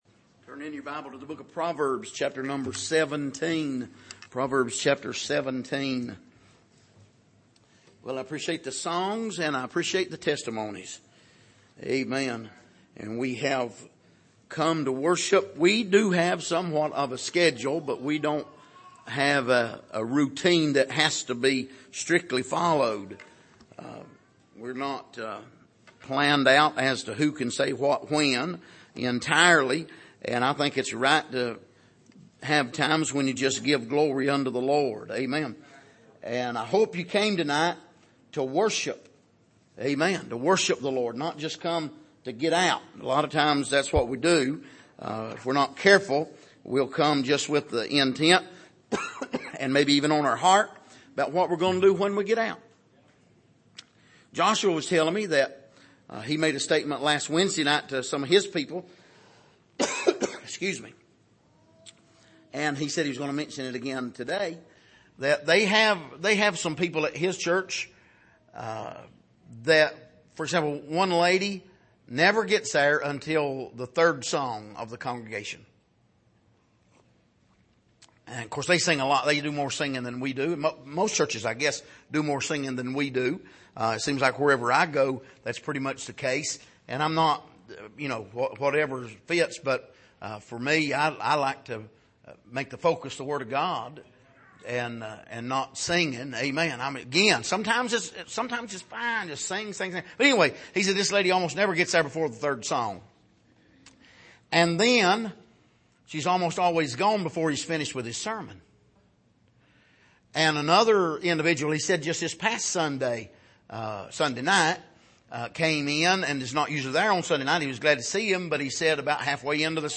Passage: Proverbs 17:1-8 Service: Sunday Evening